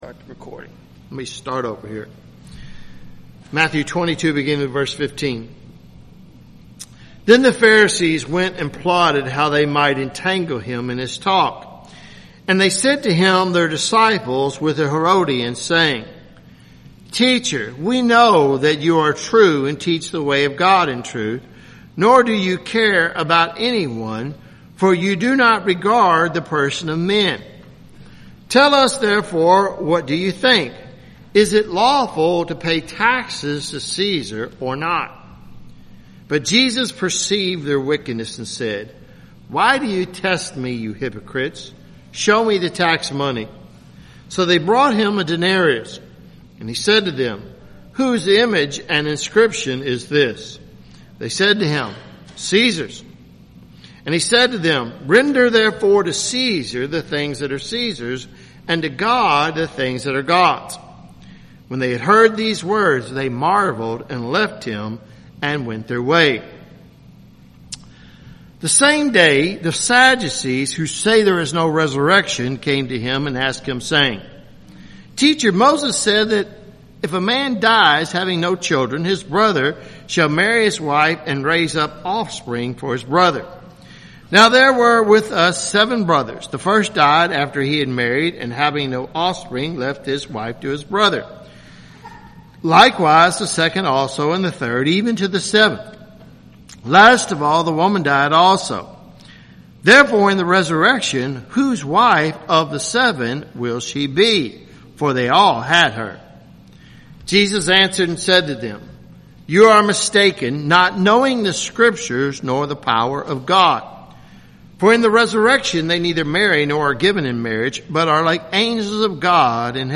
Matthew sermon series – Christ Covenant Presbyterian Church